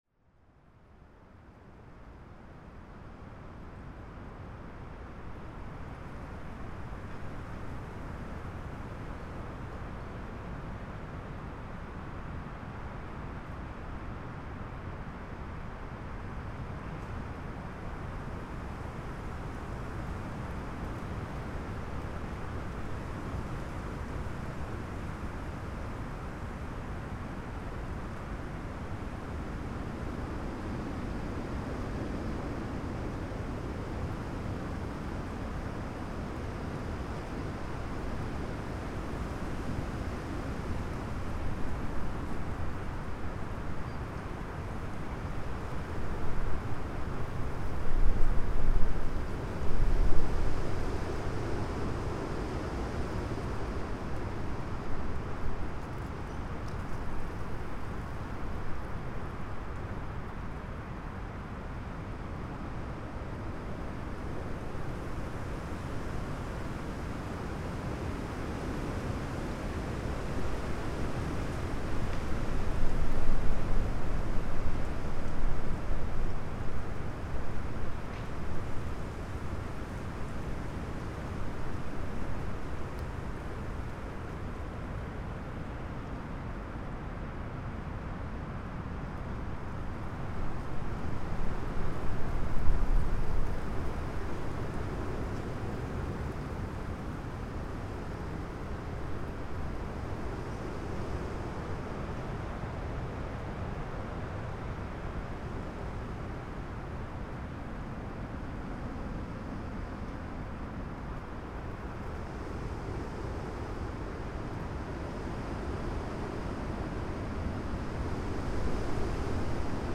PFR10379, 1-10, 141224, bubbling conches, Gollwitz, Poel Island, Germany